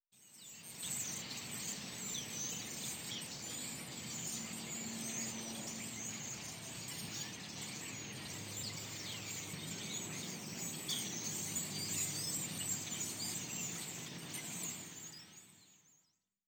sound_library / animals / birds